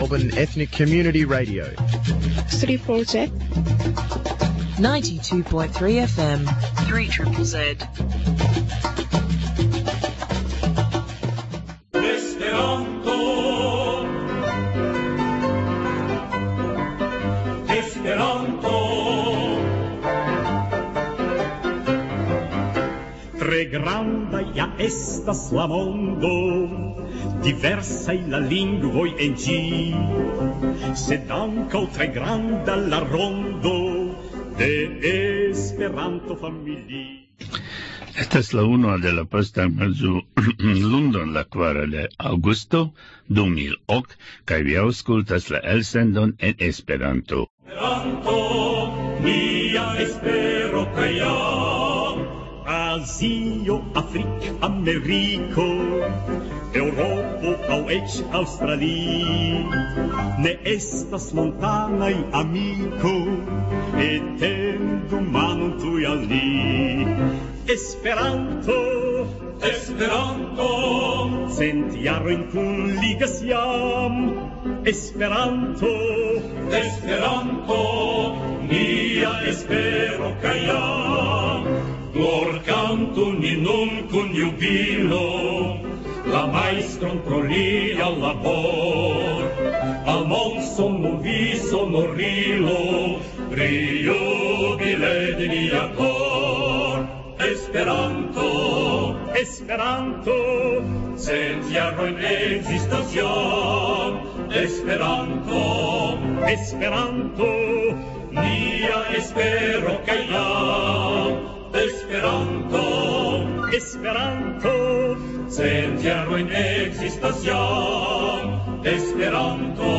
Legaĵo